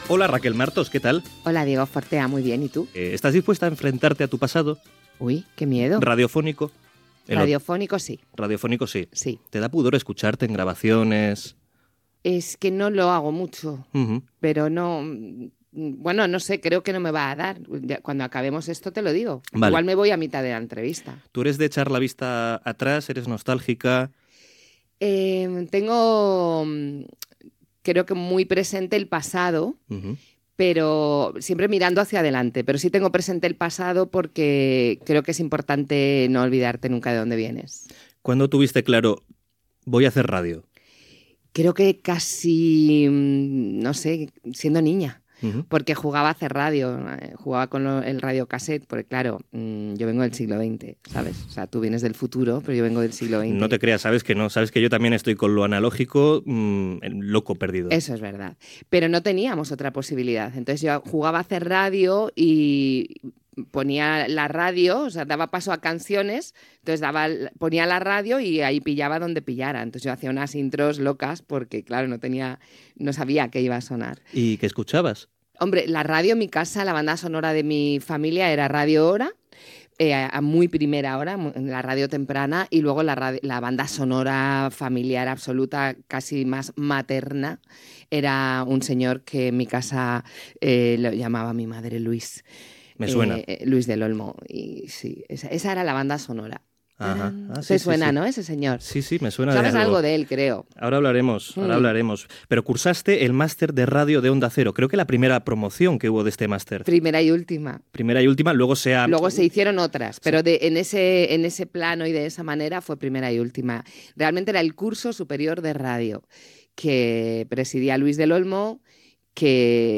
Fragment d'una entrevista a la periodista Raquel Martos sobre la seva trajectòria a la ràdio.